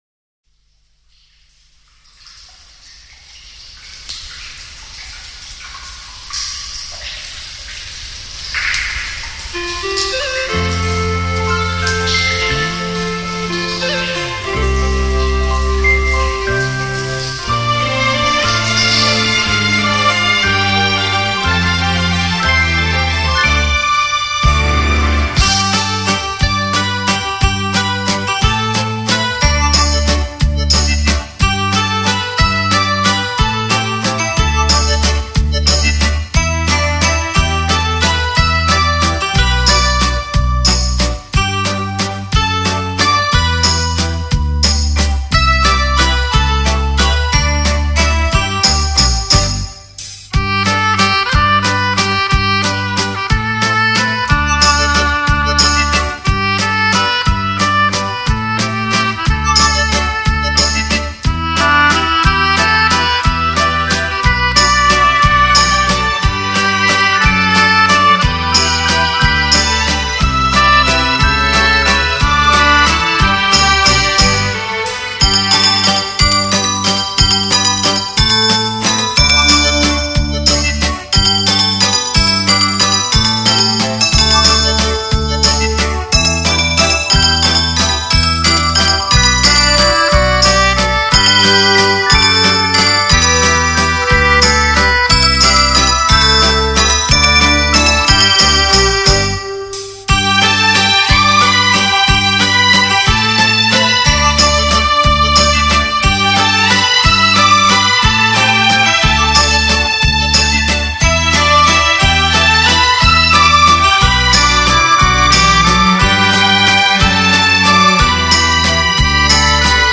[亚洲纯音]
音乐类型： 民乐